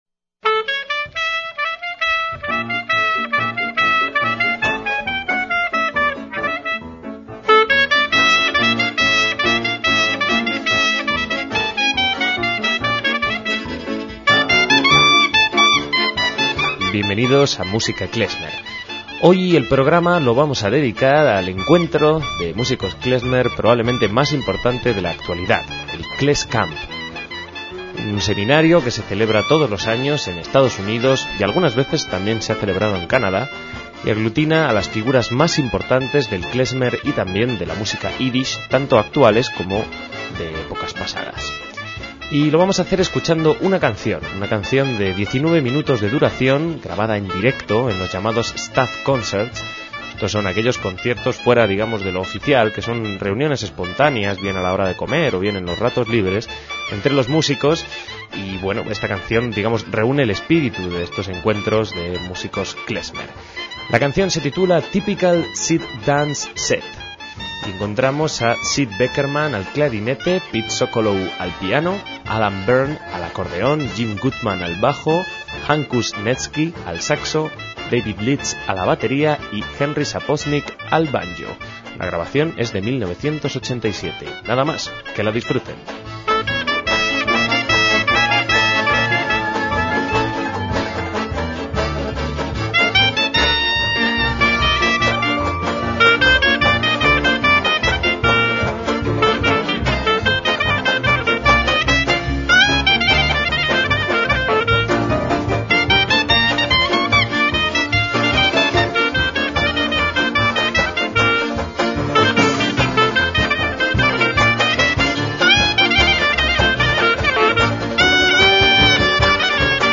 Jam session en KlezKamp
MÚSICA KLEZMER